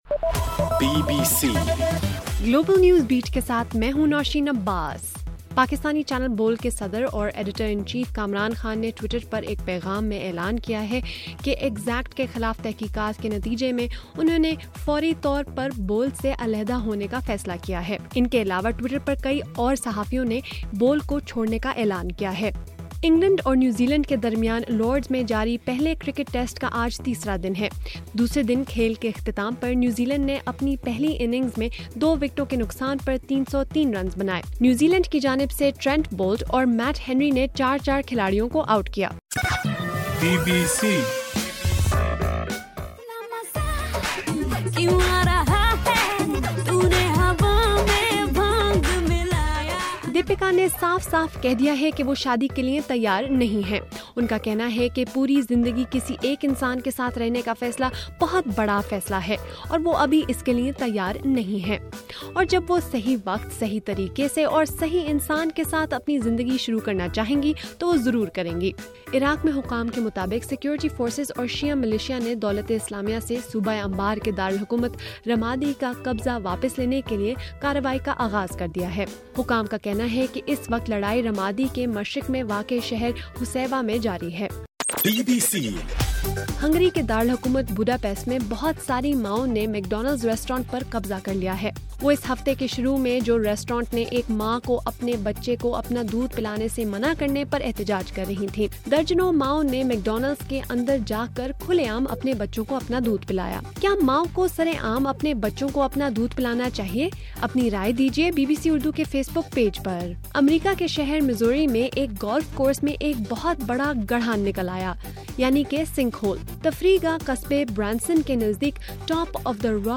مئی 23: رات 10 بجے کا گلوبل نیوز بیٹ بُلیٹن